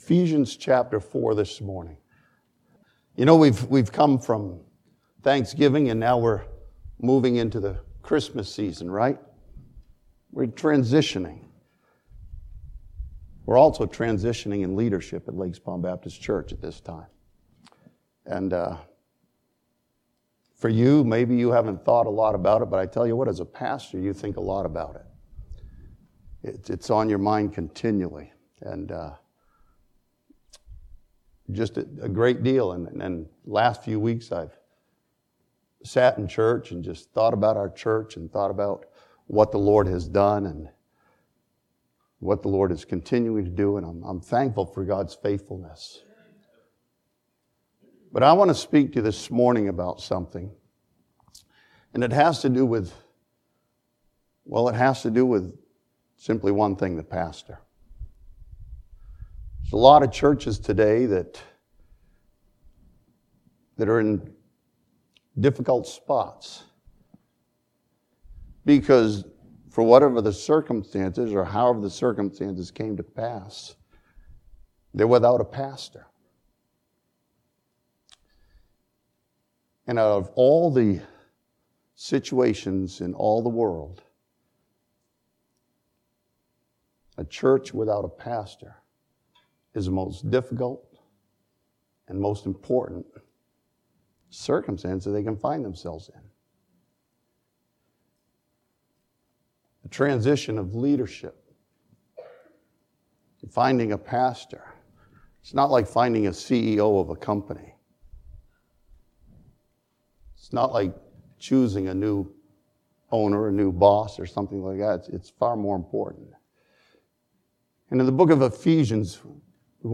This sermon from Ephesians chapter 4 lays out the essential qualities of a pastor as presented in Scripture.